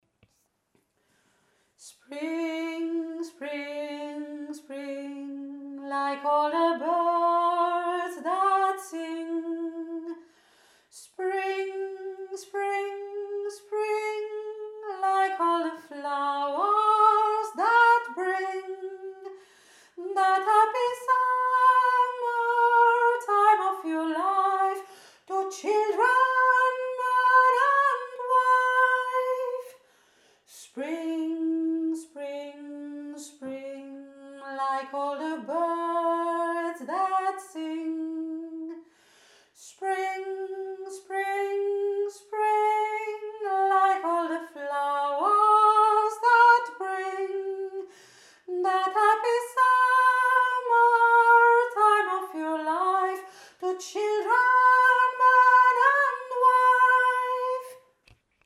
Ein Kanon der mich schon lange begleitet. Der gar nicht so einfach ist, aber einfach auch ein schönes Frühlingsgefühl vermittelt.